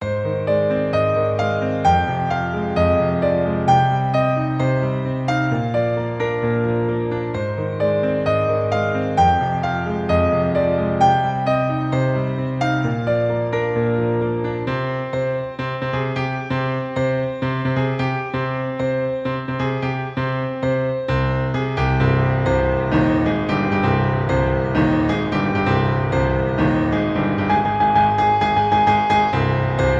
• 🎹 Instrument: Piano Solo
• 🎼 Key: C Minor
• 🎶 Genre: Pop